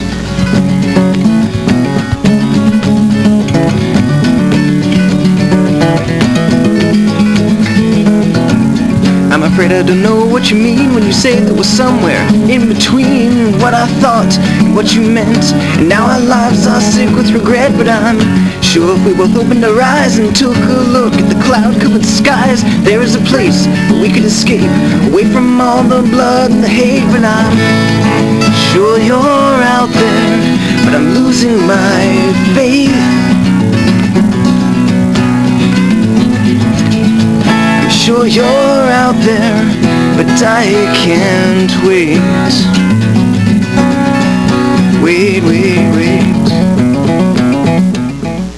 I've been writing songs on my acoustic guitar since I was sixteen.